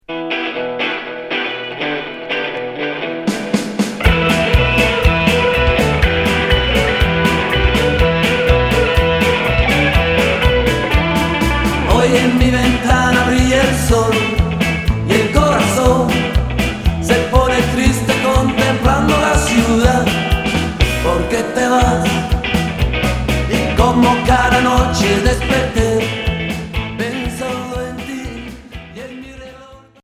Rock Deuxième 45t retour à l'accueil